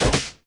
RA_El_Tigre_atk_clean_001.wav